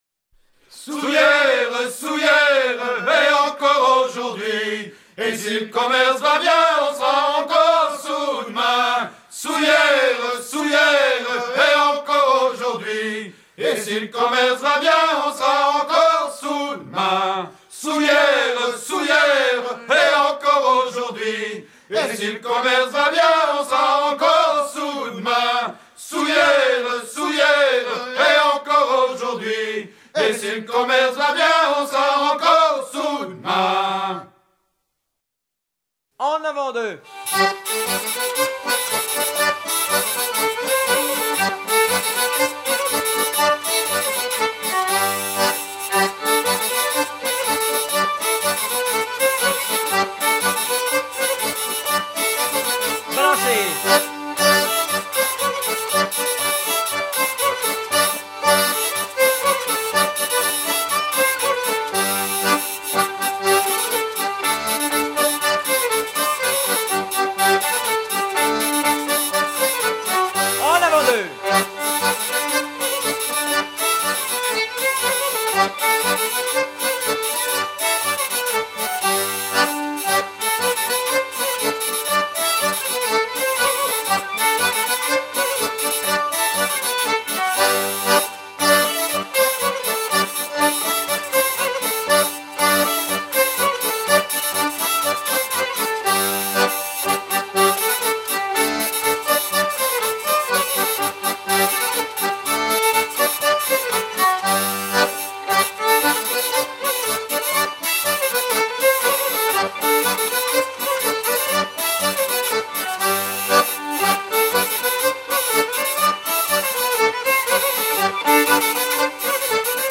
Saouls hier - En avant-deux Détails de l'archive Titre Saouls hier - En avant-deux Origine du titre : Editeur Note version recueillie à Saint-Malo en 1993.
danse : branle : avant-deux
circonstance : maritimes ; circonstance : bachique